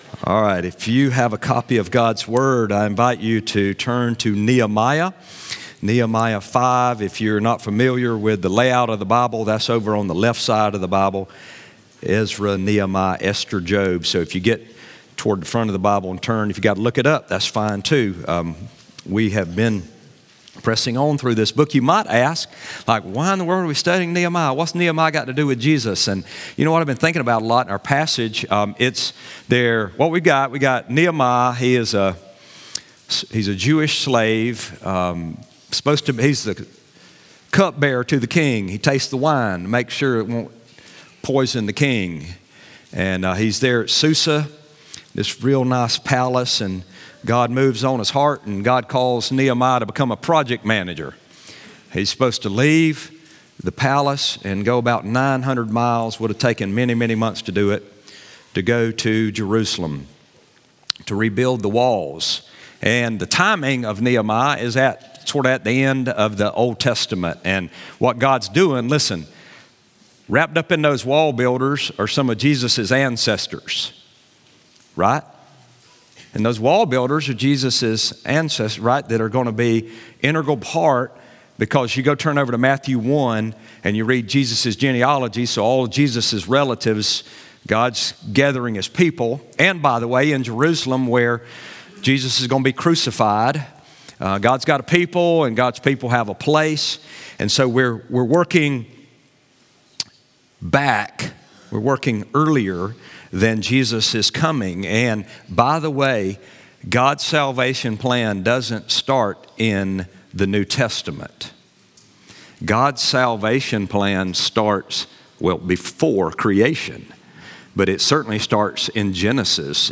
Listen to the sermon CONFLICT WITHIN